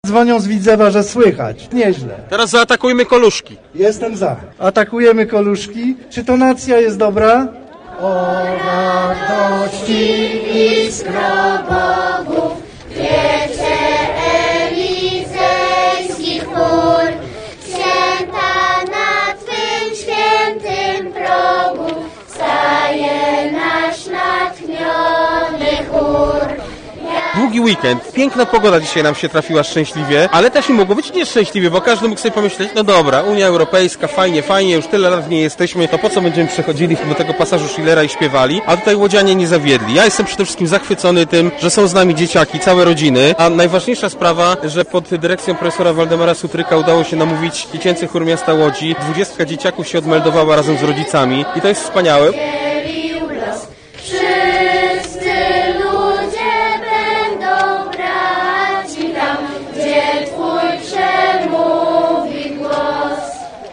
W centrum miasta kilkaset osób wspomagało śpiewem Dziecięcy Chór Miasta Łodzi, który dwukrotnie odśpiewał hymn Unii Europejskiej.
Występom towarzyszyła wielometrowa flaga unijna trzymana przez zgromadzoną publiczność.